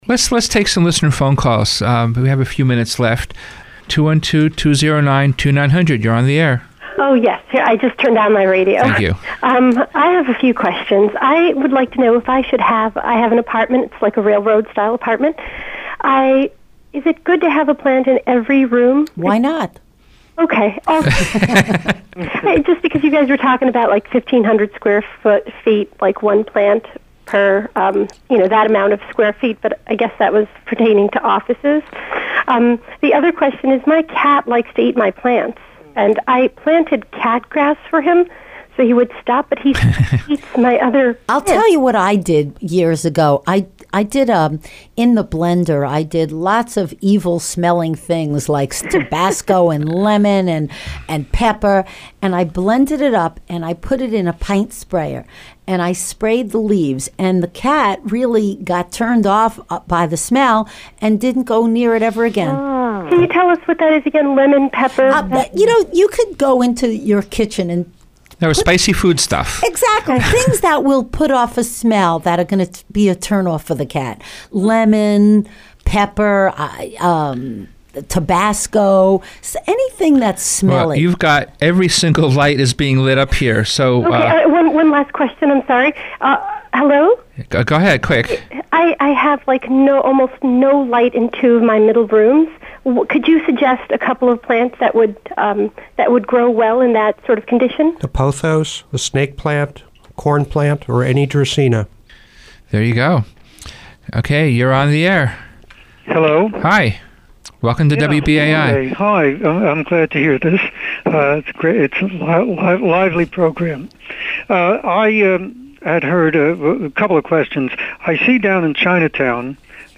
Health Effects of Indoor Plants listener phone calls mp3 9:27. Listener phone calls.